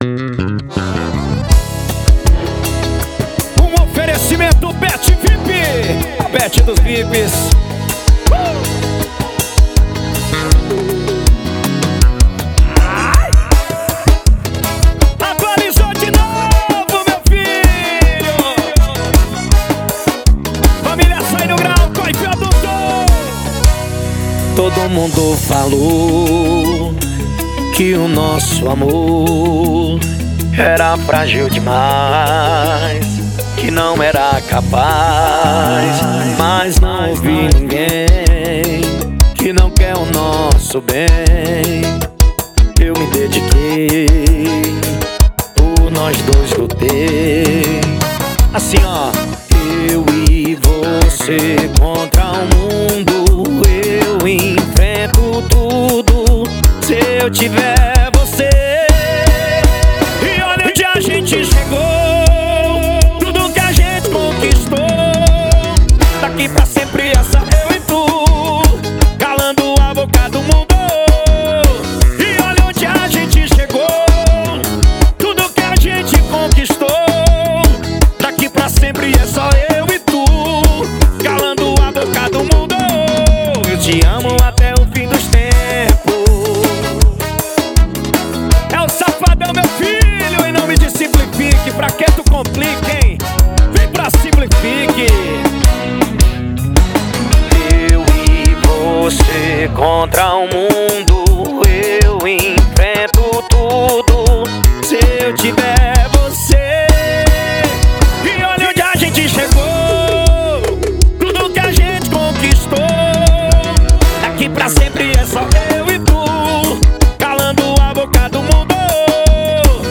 2024-02-14 22:38:23 Gênero: Forró Views